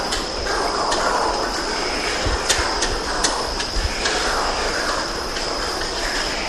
tłuszczak (Steatornis caripensis) - klekot dziobem ptak żyjący w wilgotnych lasach Ameryki Południowej, w sąsiedztwie jaskiń, w których ma kolonie lęgowe.